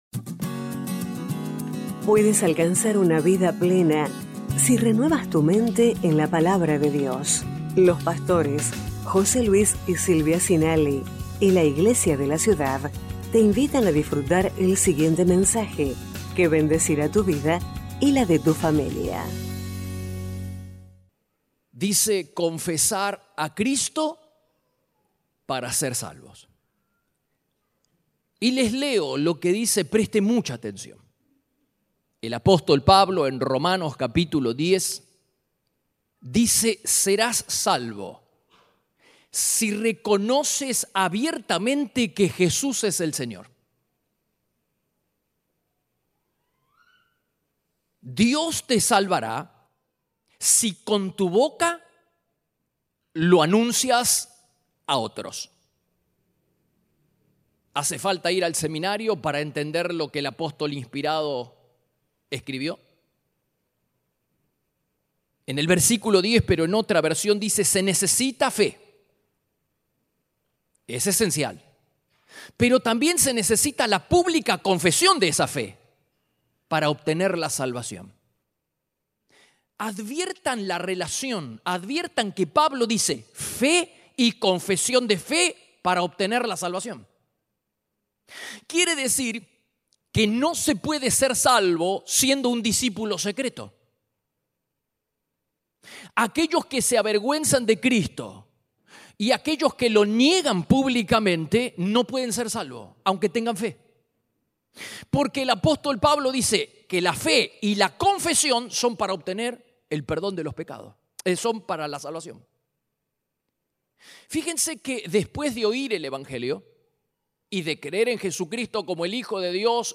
Iglesia de la Ciudad - Mensajes